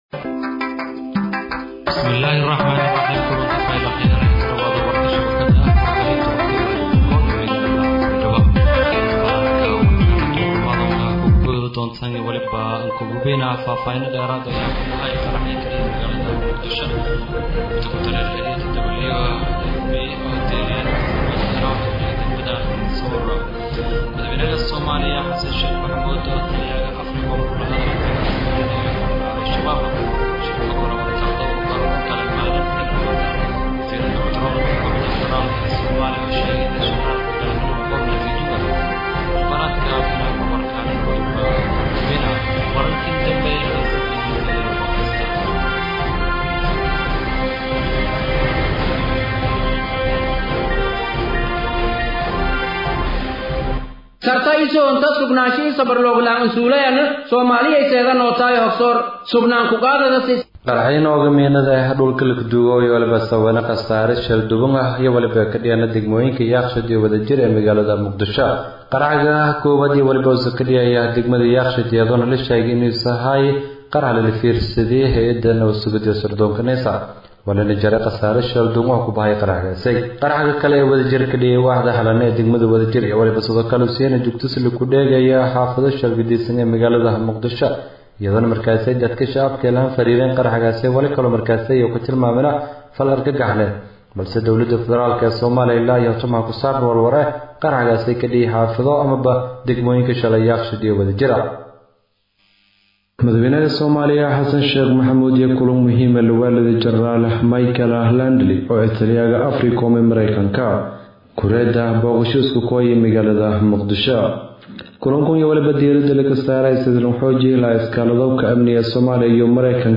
{DHAGEYSO} Warka Duhurnimo ee Warbaahinta Radio Codka Baay Iyo Bakool {07.05.2025}